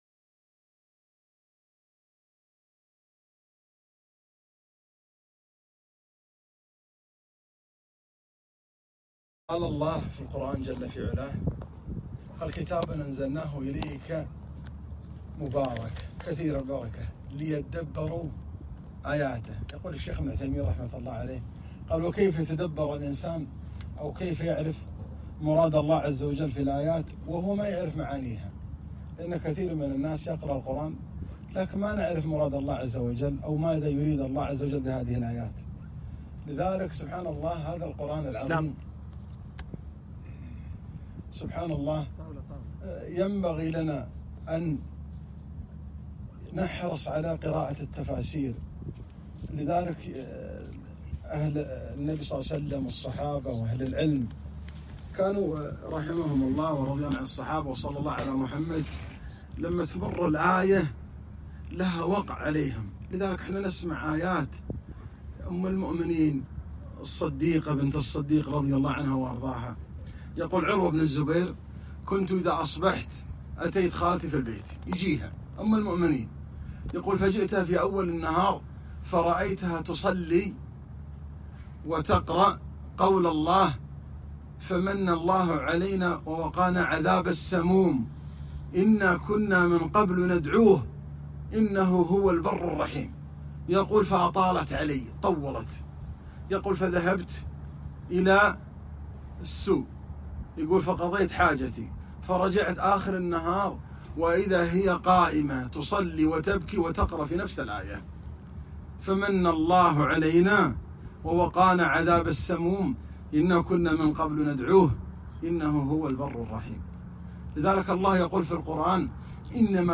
كلمة - القرآن الكريم